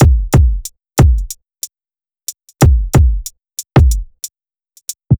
HP092BEAT3-R.wav